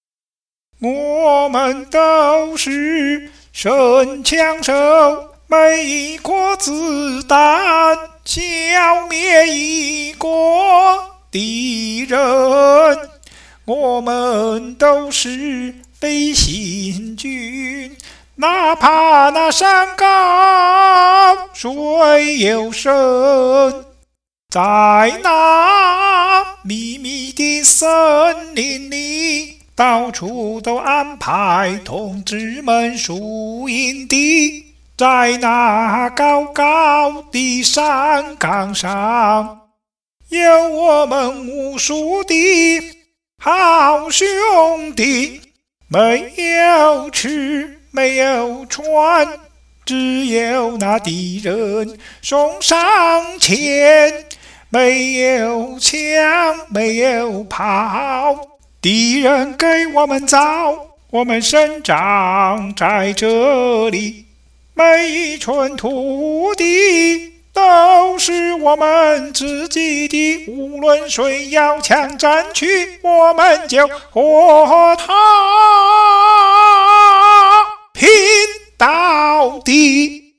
来不及做伴奏了，下次补上。